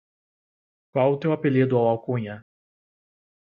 Read more Det Pron Noun Intj Frequency A1 Pronounced as (IPA) /tew/ Etymology Inherited from Latin tuus In summary From Old Galician-Portuguese tou, from Latin tuus, from Proto-Italic *towos.